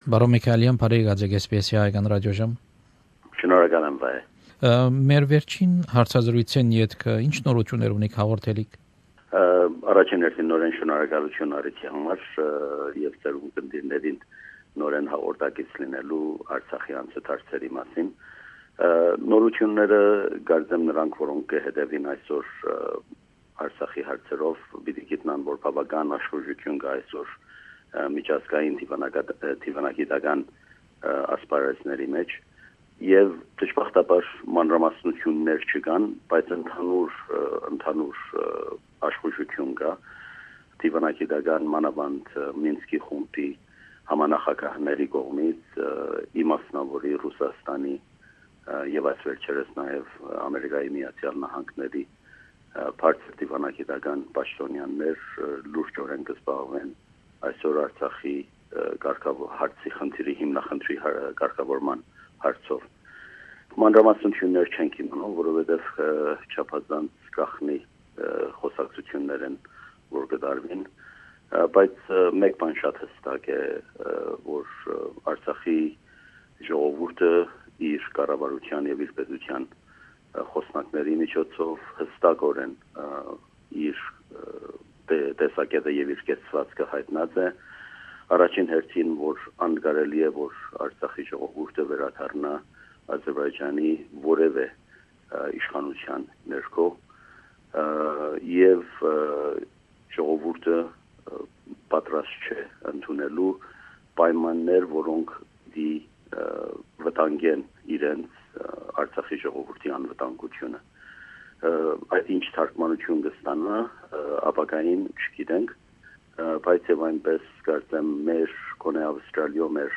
An interview with Kaylar Michaelian, the Permanent Representative of Nagorno Karabakh Republic to Australia.